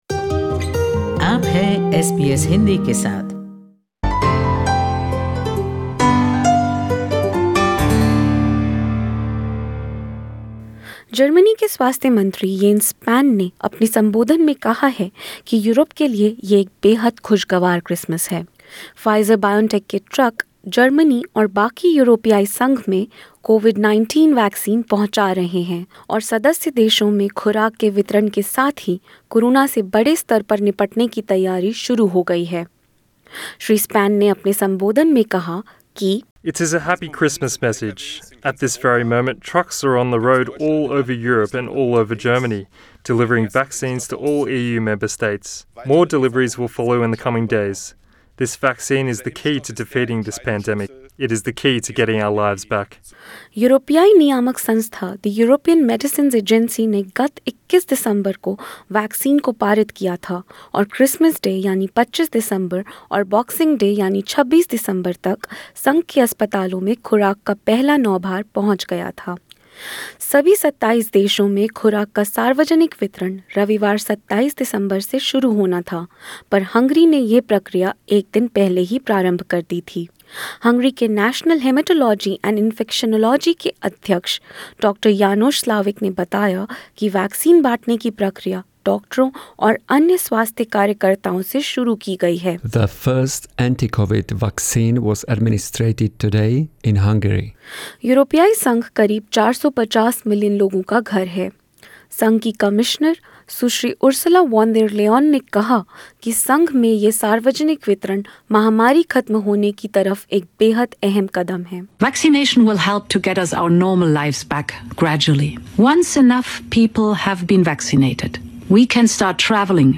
यह विस्तृत रिपोर्ट